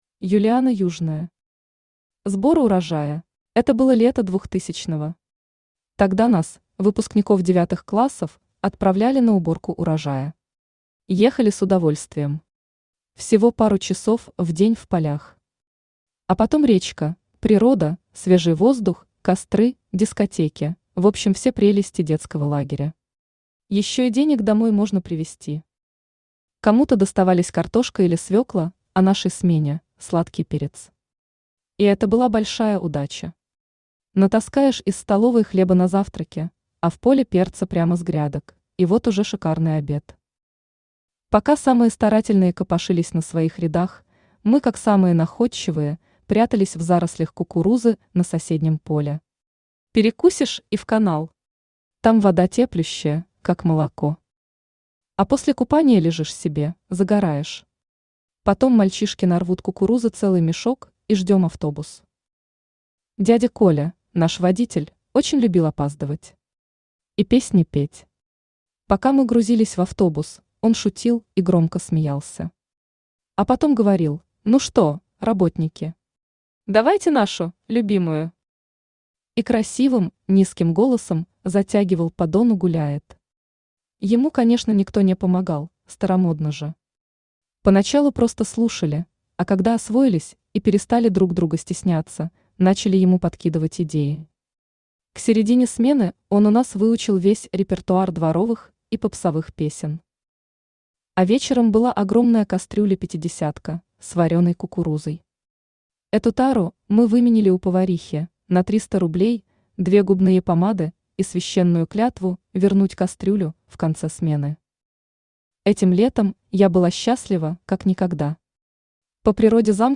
Аудиокнига Сбор урожая | Библиотека аудиокниг
Aудиокнига Сбор урожая Автор Юлиана Южная Читает аудиокнигу Авточтец ЛитРес.